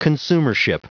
Prononciation du mot consumership en anglais (fichier audio)
Prononciation du mot : consumership